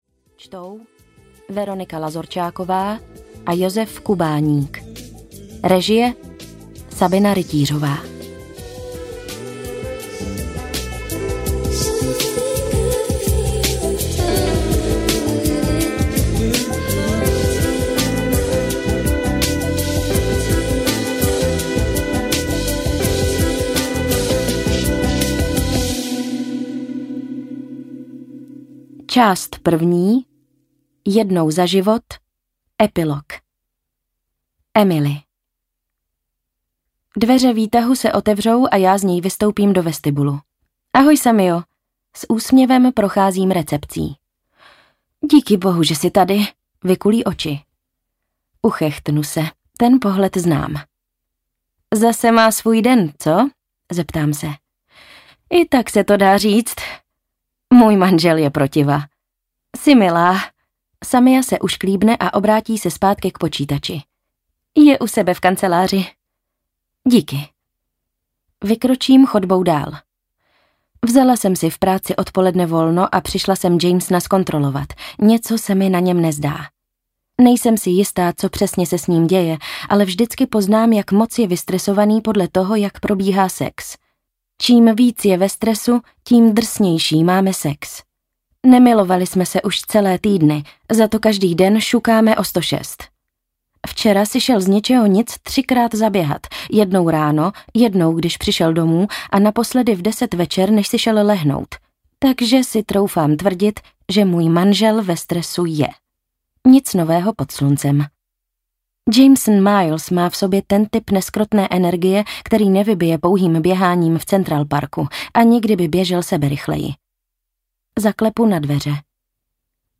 A co bylo dál audiokniha
Ukázka z knihy